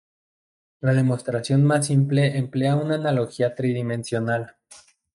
Pronounced as (IPA)
/tɾidimensjoˈnal/